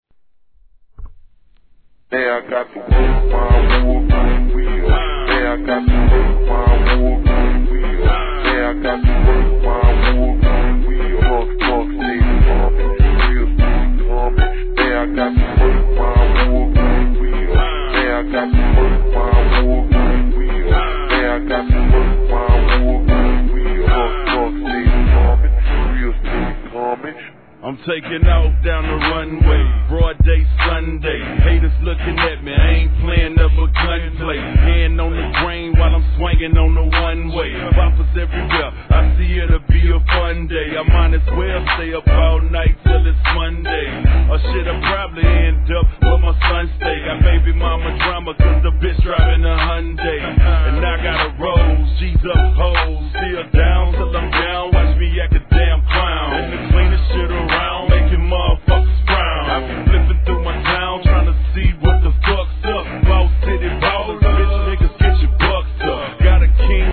HIP HOP/R&B
なんともG-RAPファン泣かせなダウナーBEATにスクリュードされたフックが雰囲気ありまくり!!